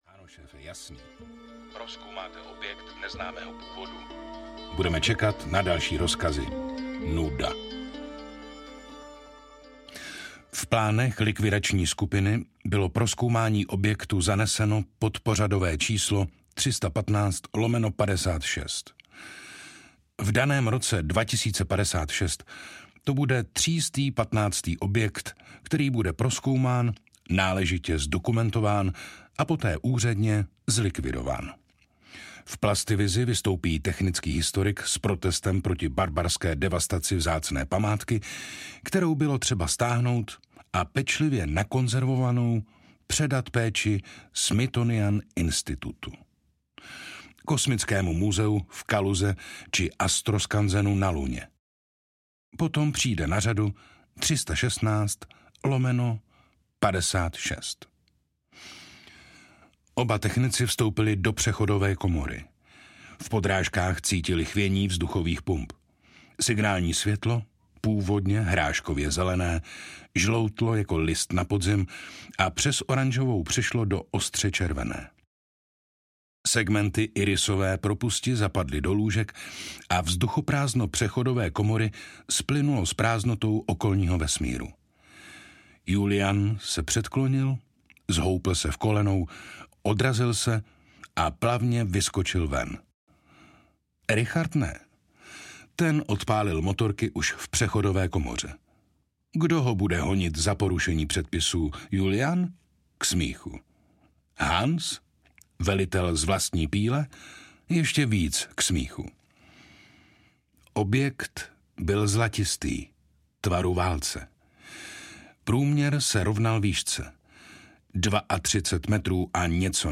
Neffova Odysea 2022: Bludiště audiokniha
Ukázka z knihy
• InterpretJiří Dvořák